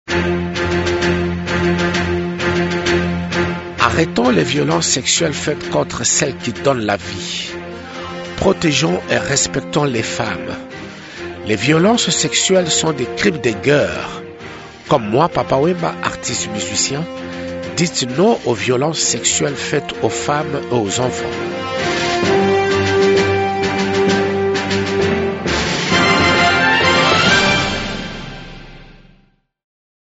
Ecoutez ici les messages de Papa Wemba, chanteur et leader d’opinion congolais, à l’occasion de la campagne de lutte contre les violences faites aux femmes et aux enfants: